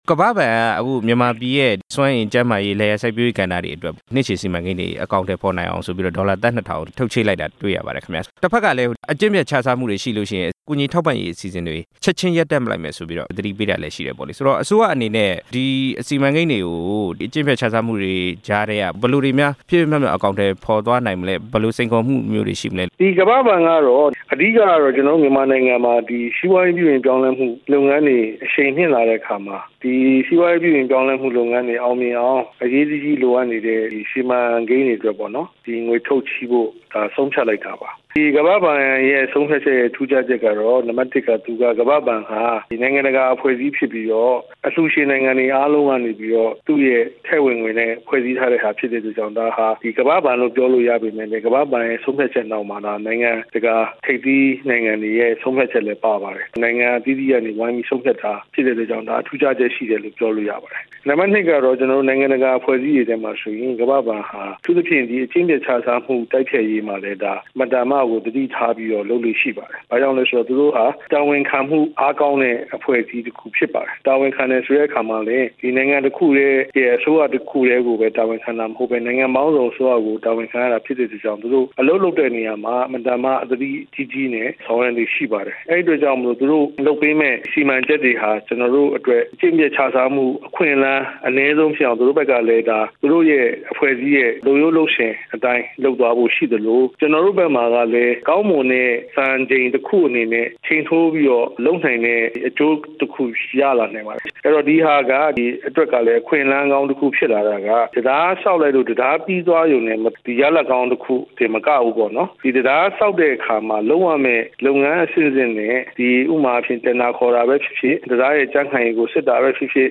ကမ္ဘာ့ဘဏ်အကူအညီ သမ္မတ စီးပွားရေးအကြံပေးနဲ့ မေးမြန်းချက်